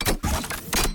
Terminal_open.ogg